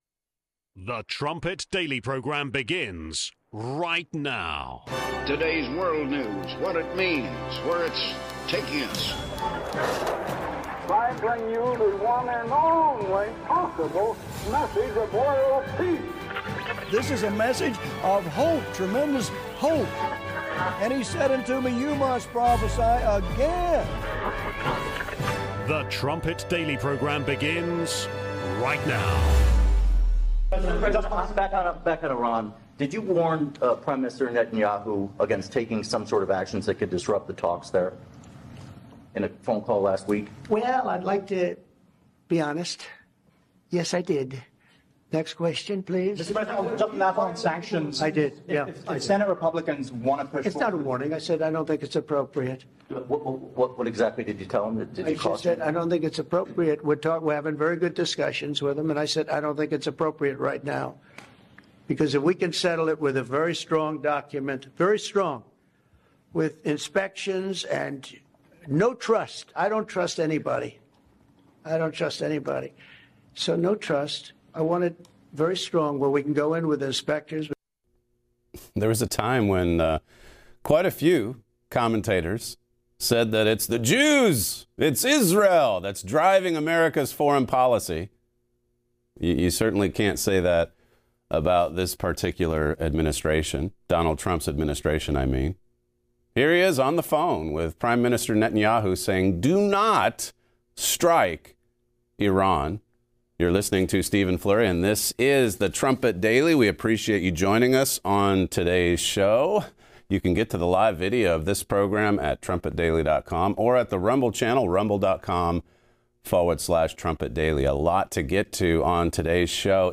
32:00 Melanie Phillips Interview, Part 3 (23 minutes)